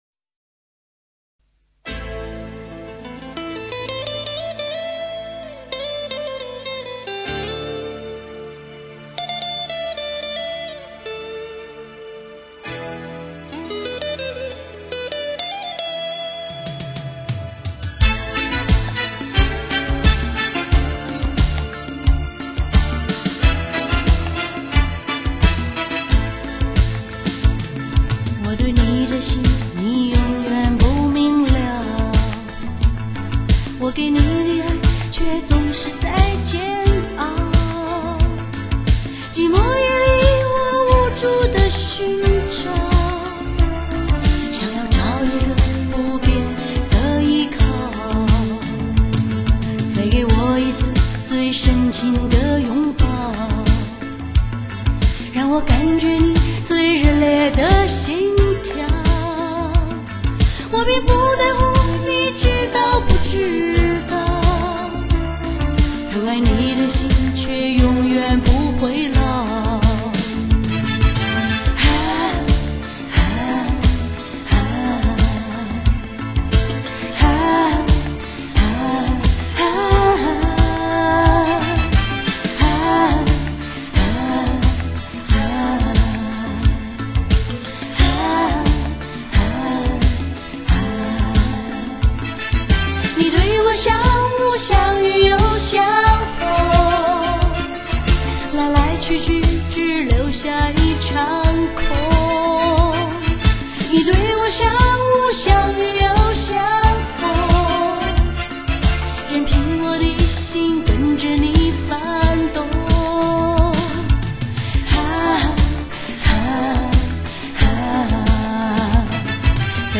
音乐风格:流行